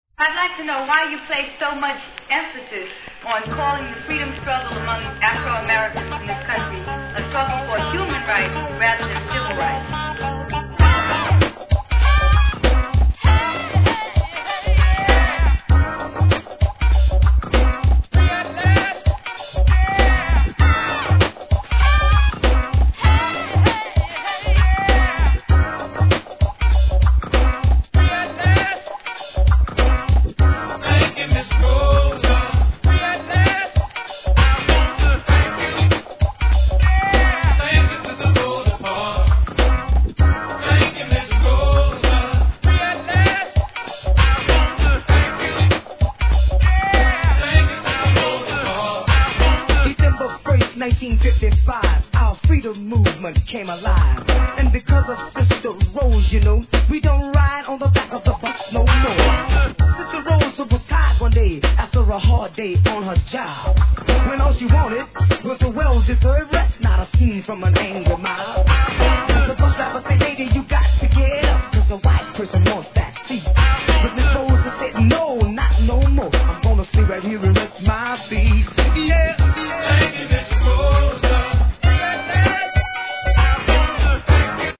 HIP HOP/R&B
そのシリアスな雰囲気を押し出したLP ver.に加え、よりFUNKYなREMIXも収録です!